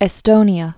(ĕ-stōnē-ə)